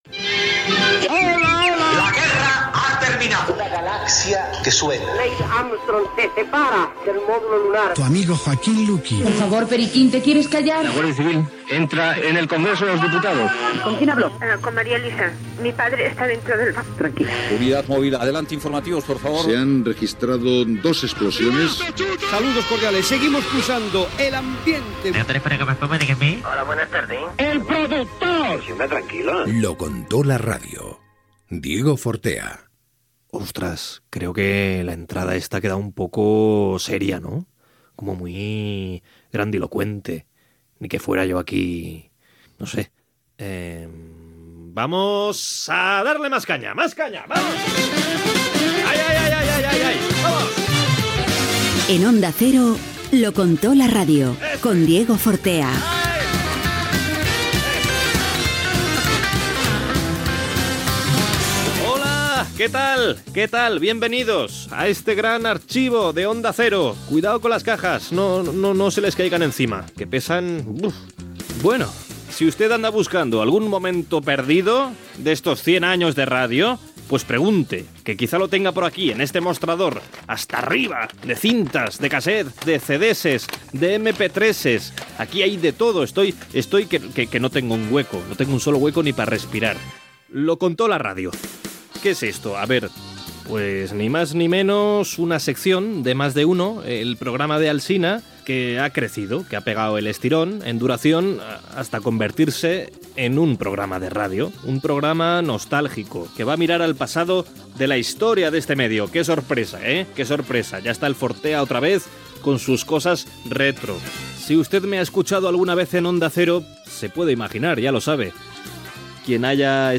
Careta, presentació del primer programa
Entreteniment
FM